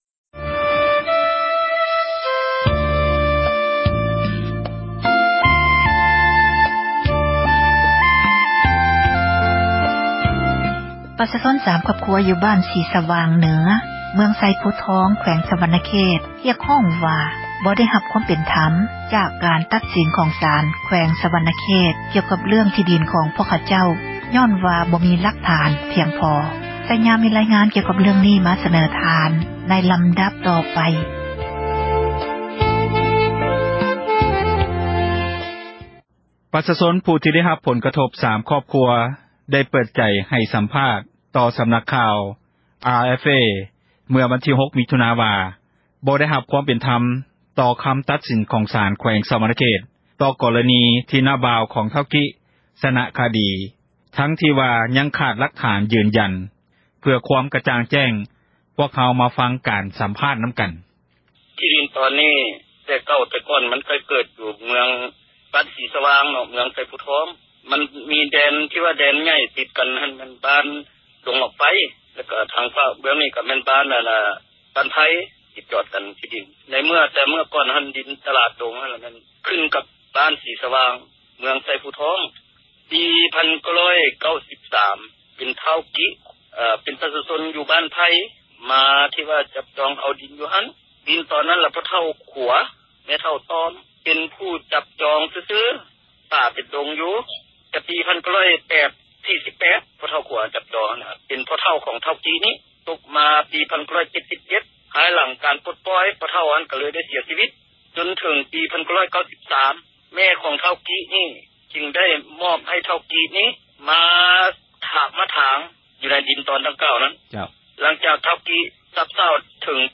ດັ່ງ ປະຊາຊົນ ຜູ້ໄດ້ຮັບຜົລກະທົບ ເສັຽຫາຍ 3 ຄອບຄົວ ໄດ້ເປີດໃຈໃຫ້ສັມພາດ ຕໍ່ນັກຂ່າວ RFA ເມື່ອວັນທີ 06 ມິຖຸນາ ວ່າ :
ເພື່ອຄວາມກະຈ່າງແຈ້ງ, ພວກເຮົາ ມາຟັງການສັມພາດນຳກັນ.